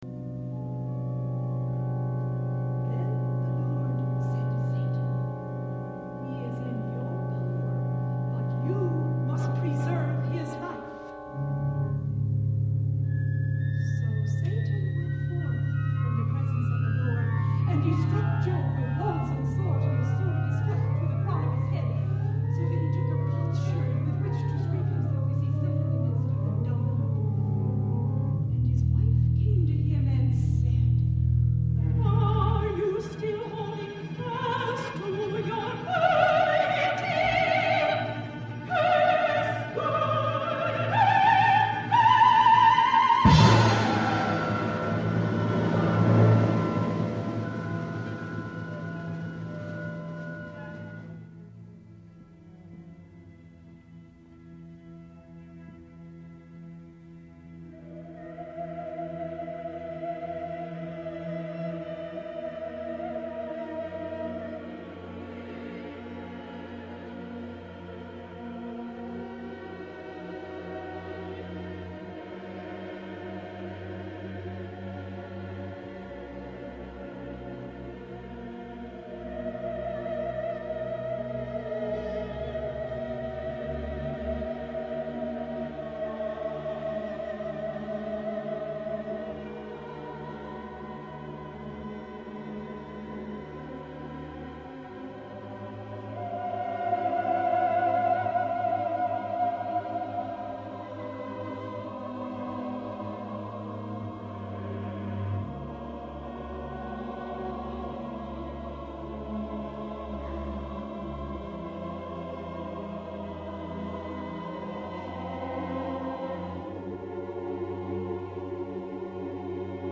This work is typical of his dissonant style with its avoidance on consonant intervals, employment of disjointed melodic lines, and use of contemporary contrapuntal techniques.